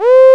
VOICE C4 F.wav